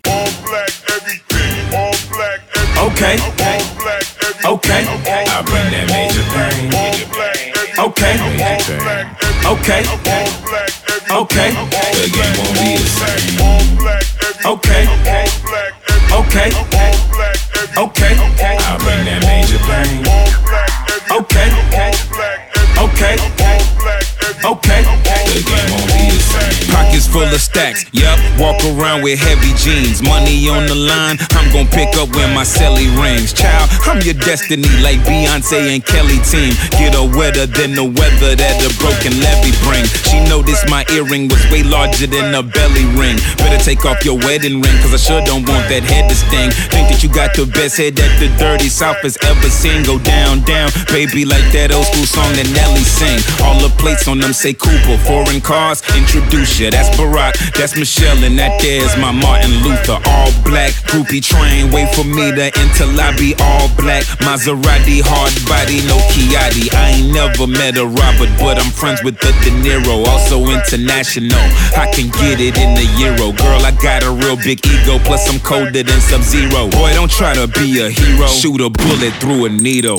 Genre: Hip Hop.